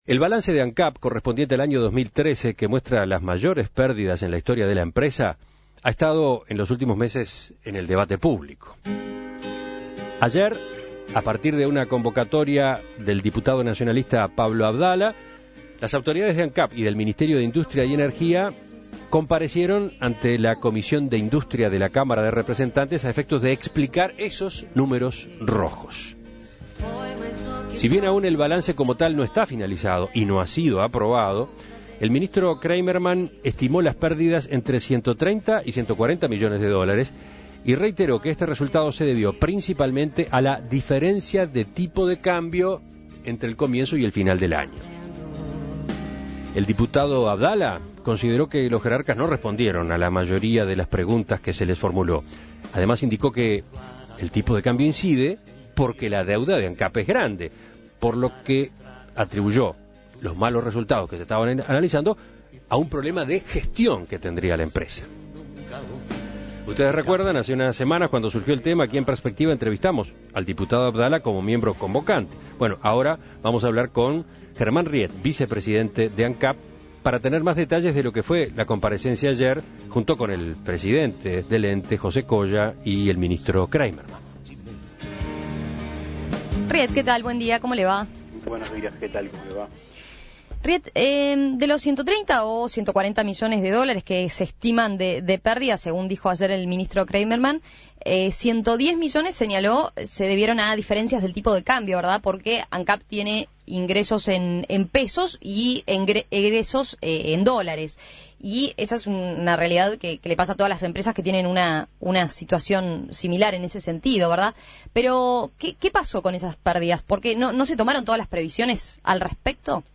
En la jornada de este miércoles comparecieron en el Parlamento el ministro de Industria, Roberto Kreimerman, y autoridades de Ancap por el tema de la deuda del ente. En dicha comparecencia, el ministro reiteró que las pérdidas contabilizadas se deben principalmente a las diferencias en el tipo de cambio a lo largo del año 2013. Para profundizar en los argumentos de Ancap con respecto a las pérdidas, En Perspectiva entrevistó al vicepresidente del ente, Germán Riet.